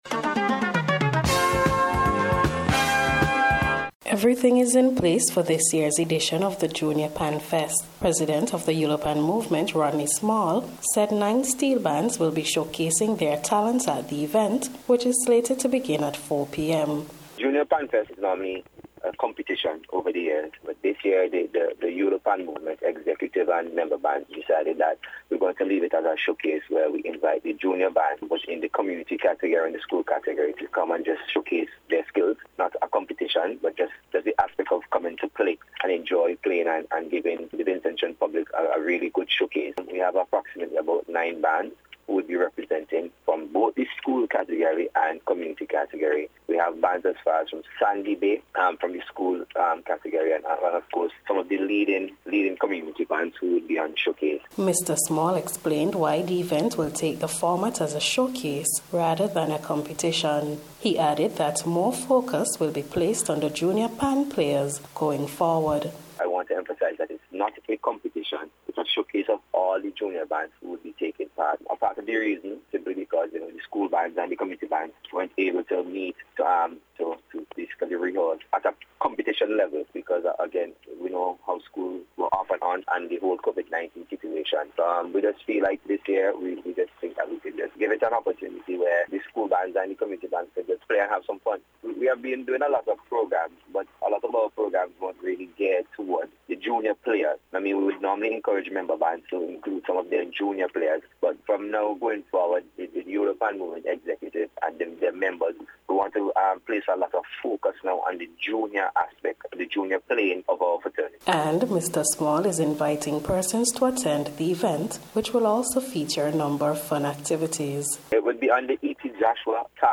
NBC’ Special Report – Thursday November 10th 2022